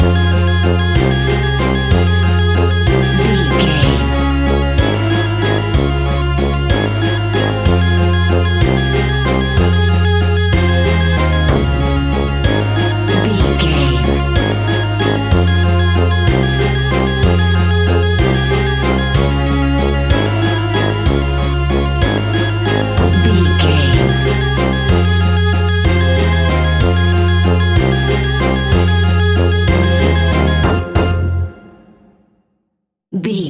Aeolian/Minor
ominous
eerie
brass
electric organ
drums
synthesiser
strings
horror music